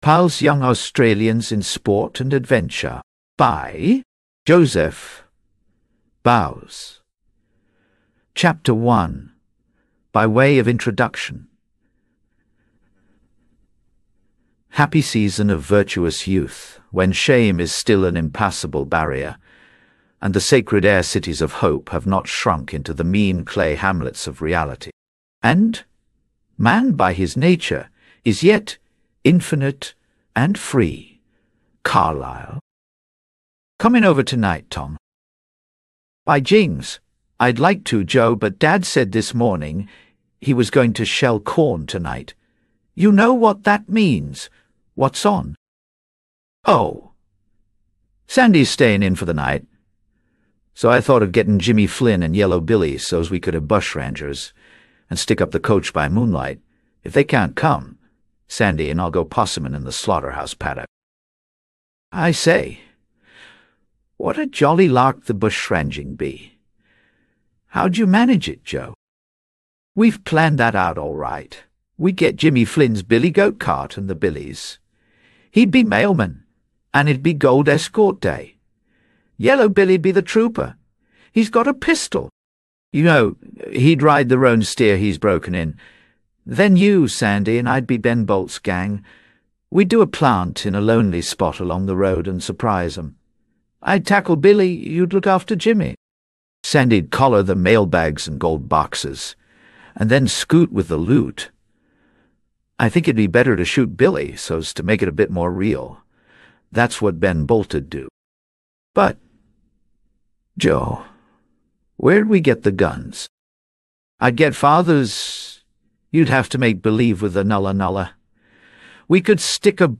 Pals_Young_Australians_in_Sport_and_Adventure_en_sample.mp3